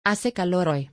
Pronunciation : ah-seh kah-lor oy